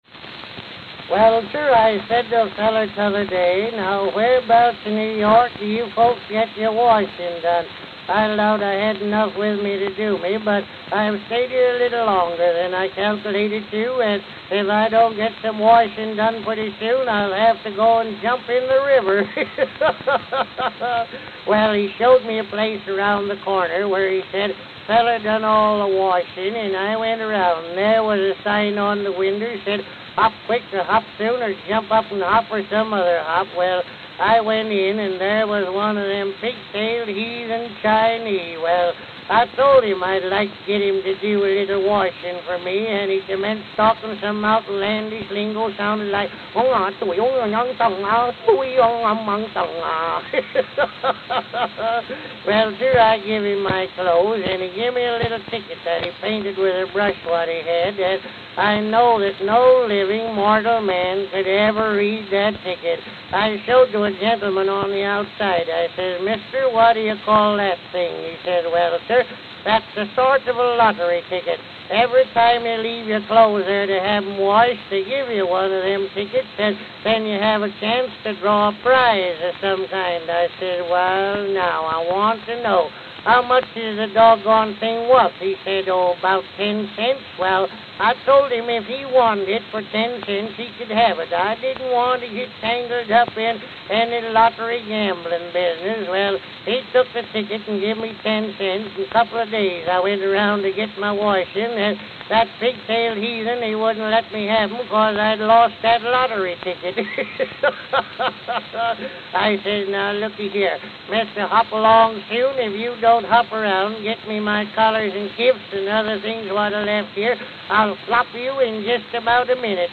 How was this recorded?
Oxford Cylinder and Disc Records Note: Worn.